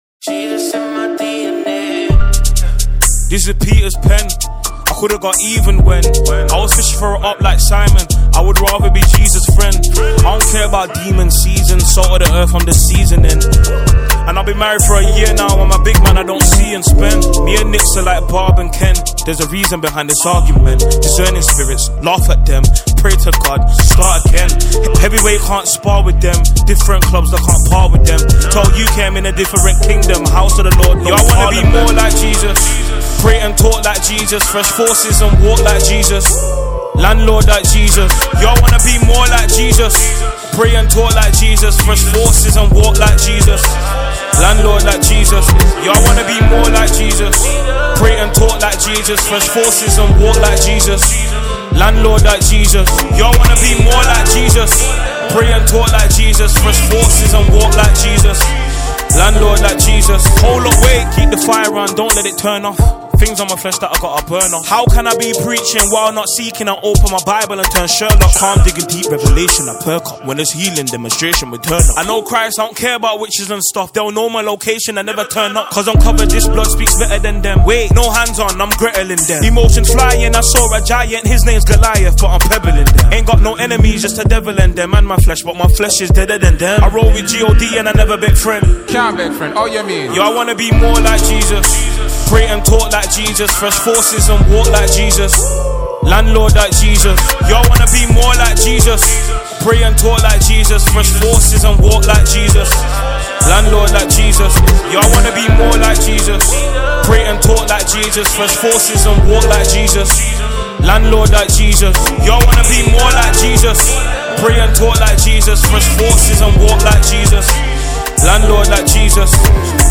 Afro Gospel Music